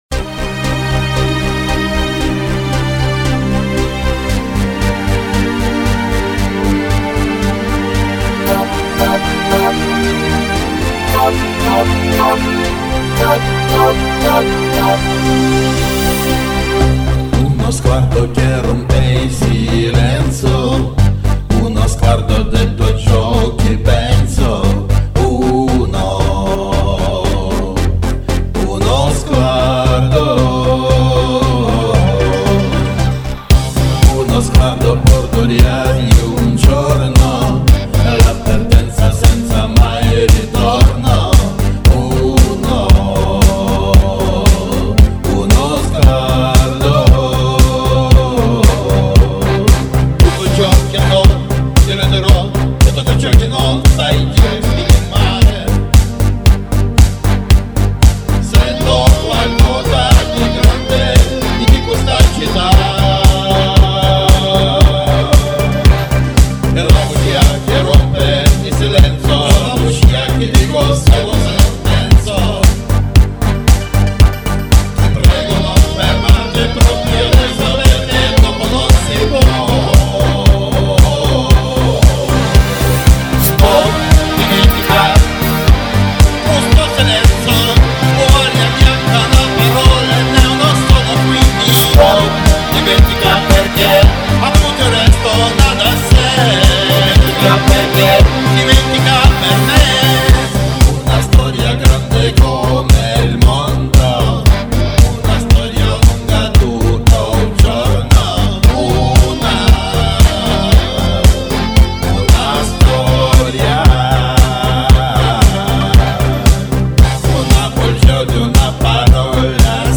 Что же поделать, если минус уже с эффектами.